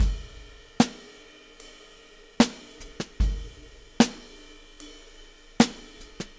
Weathered Beat 02.wav